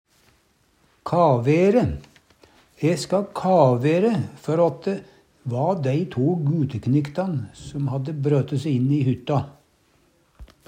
kavere - Numedalsmål (en-US)